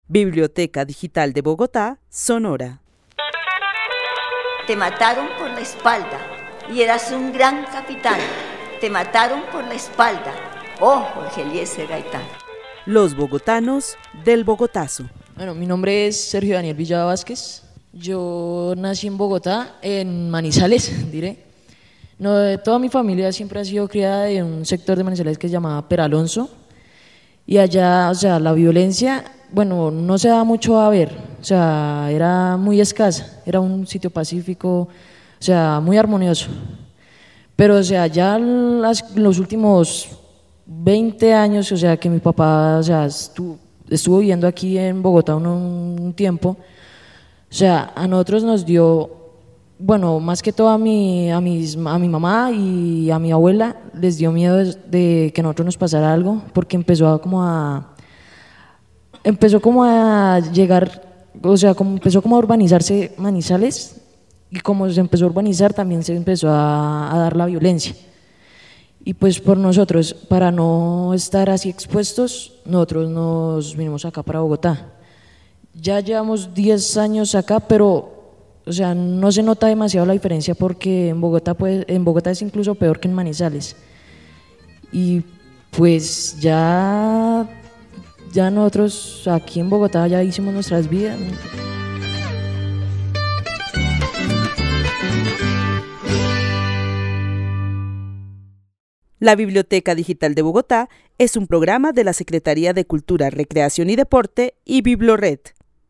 Narración oral sobre la violencia en Manizales y Bogotá.